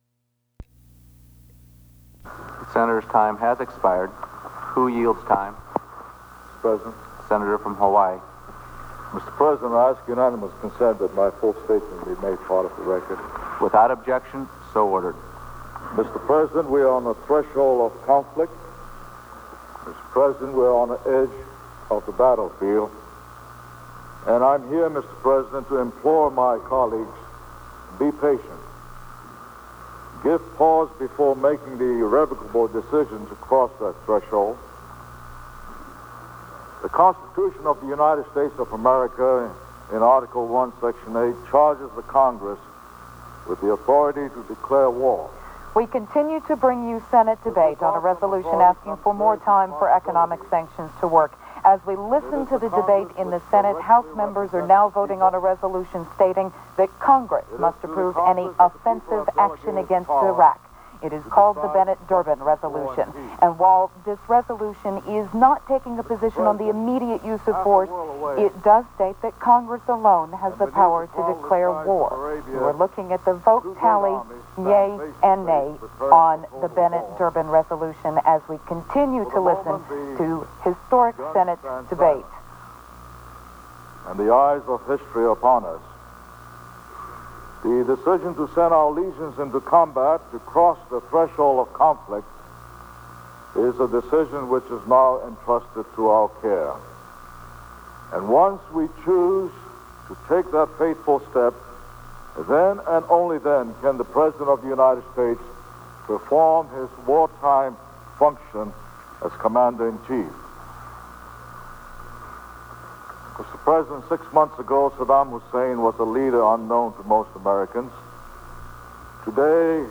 Daniel Inouye speaks on the Senate floor appealing for more time to allow sanctions to work before approving the President's request for approval of military action against Iraq